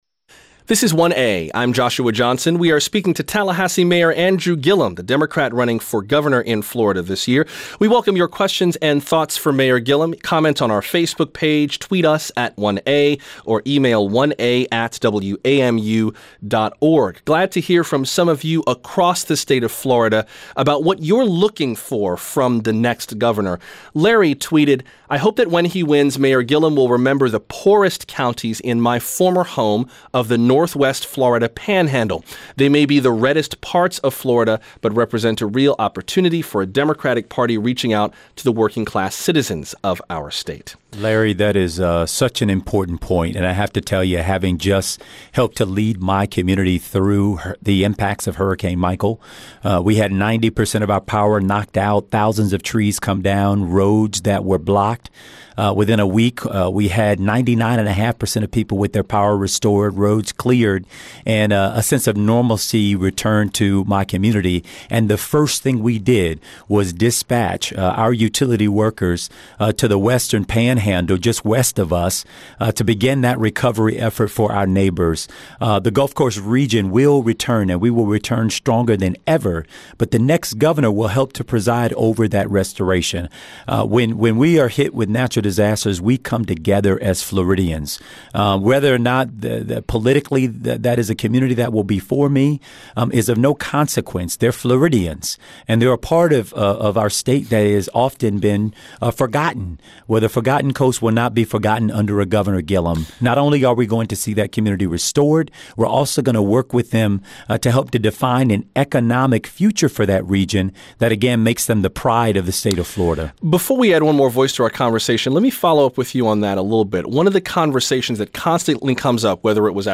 Listen to WLRN and 1A's interview with Democratic gubernatorial candidate, Andrew Gillum.